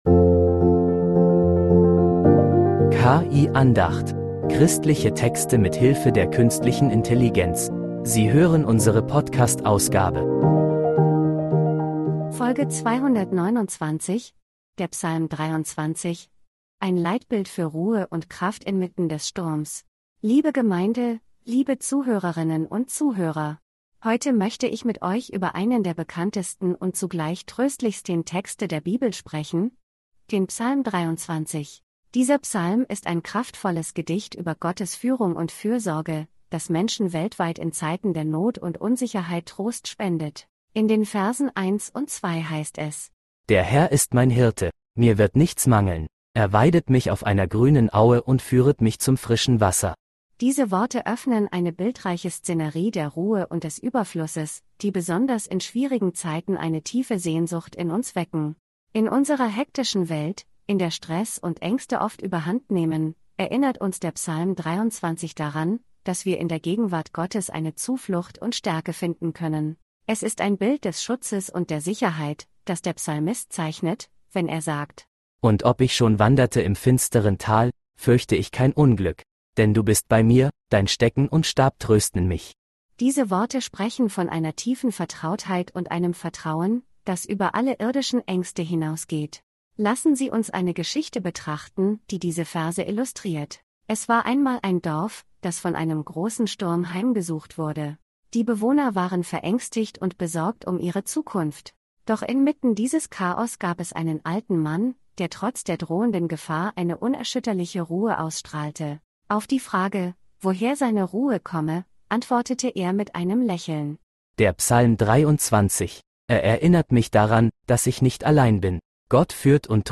In der heutigen Predigt reflektieren wir über Psalm 23, einen der